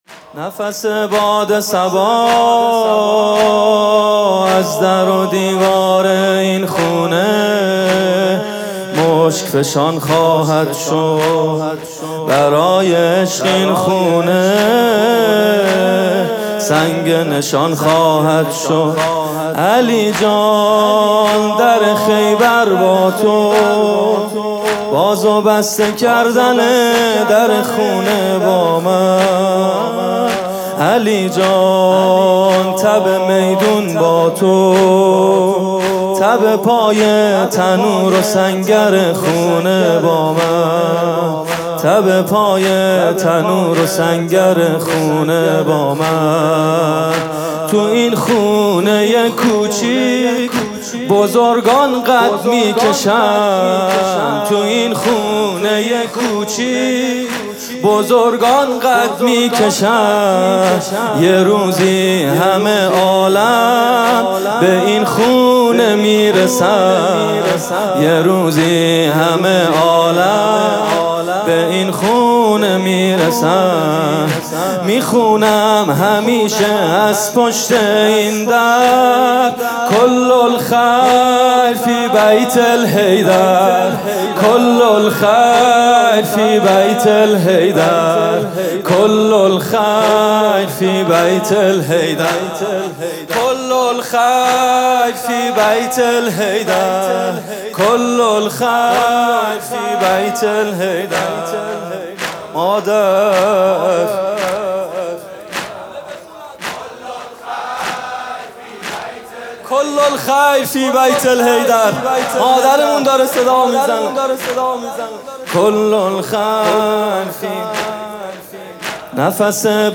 music-icon واحد: دارالتقوا، دارالاِعطاء، دارالاِنجاز، دارُ ...